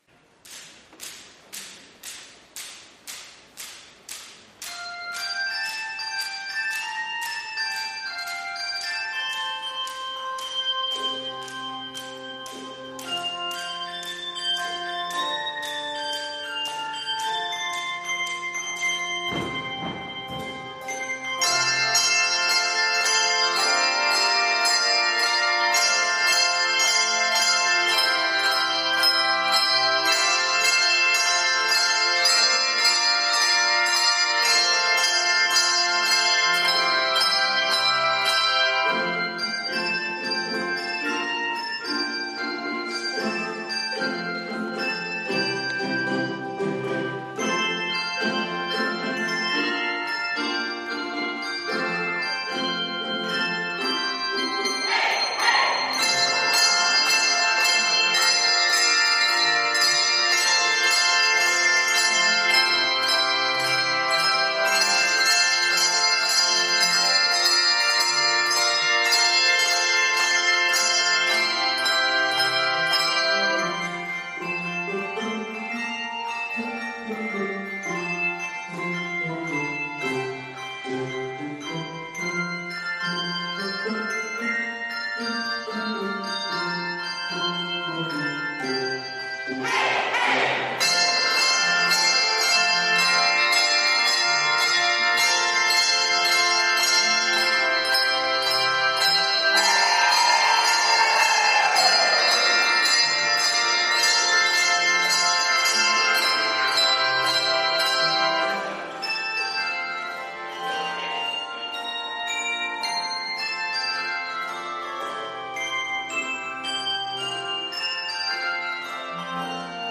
handbells
Key of F Major.